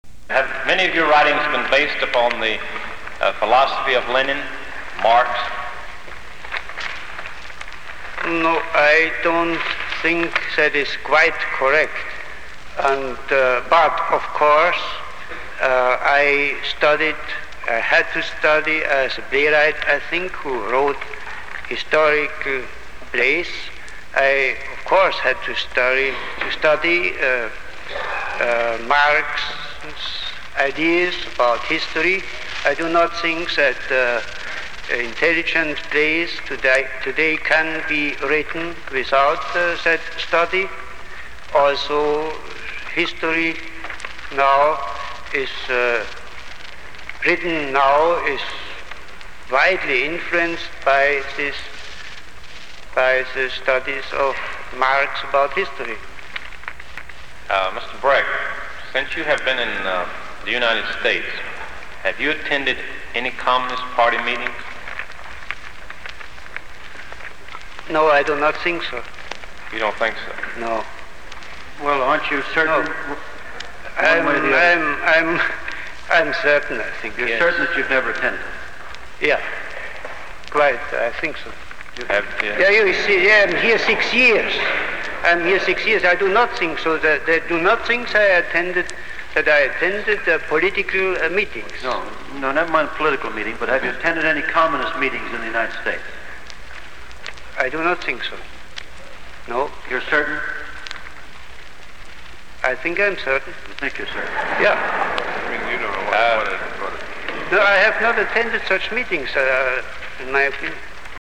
BB testified before HUAC on October 30, 1947
Brecht: full testimony, pp.207-225